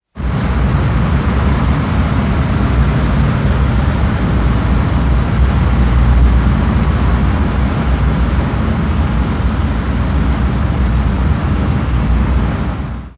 steam-cracker.ogg